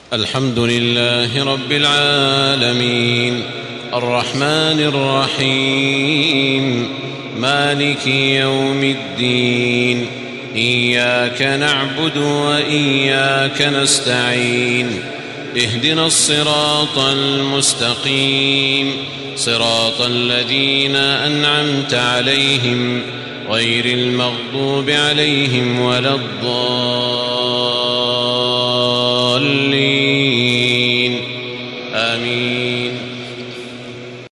تحميل سورة الفاتحة بصوت تراويح الحرم المكي 1435
مرتل